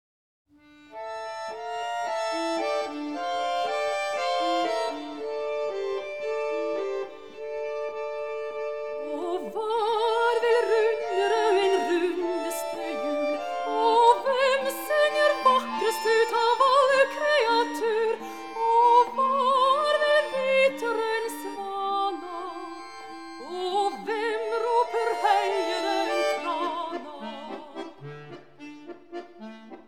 Norwegian folk music